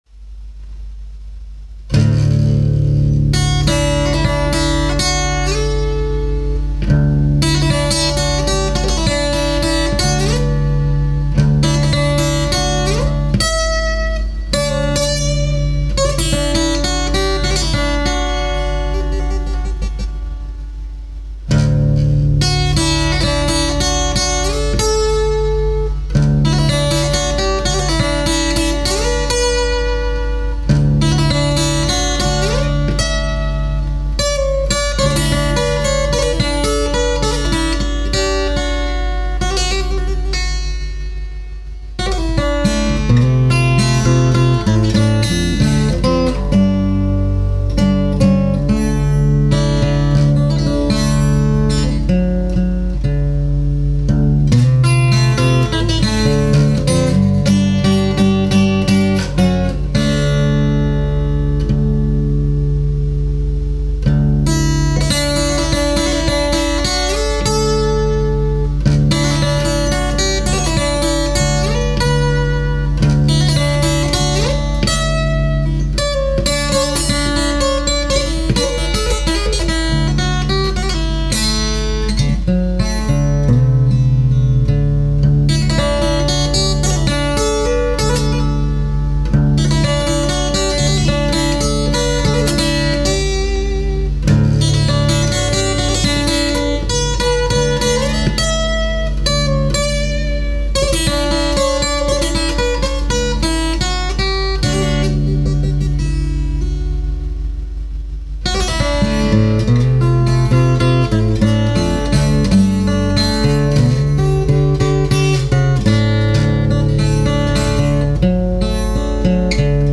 This guitar piece started as a sort of fake Irish air in DADGAD but somehow became a slide guitar piece in Csus2 tuning (if I remember rightly), by way of one or two other tunings I can’t remember right now.
Instrumental